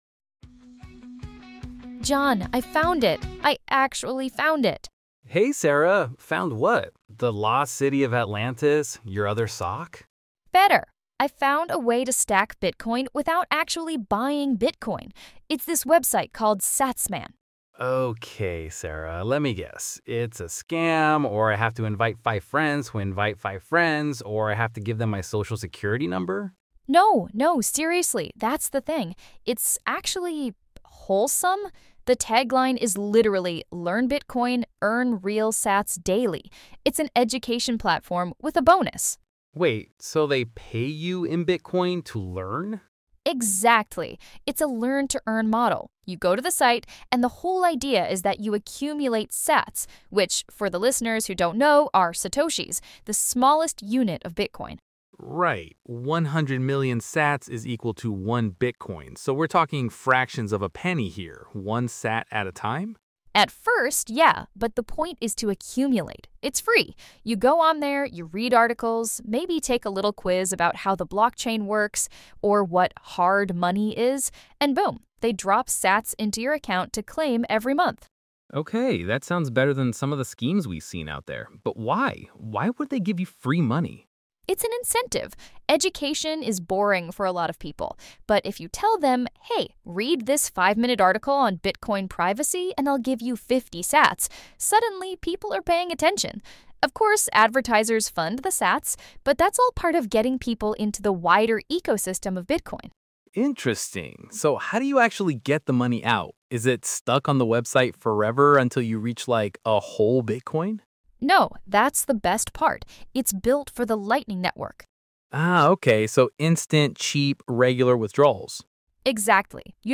A lively conversation about "Satsman," a "Learn-to-Earn" platform that rewards users with FREE Bitcoin (sats) for engaging with educational content.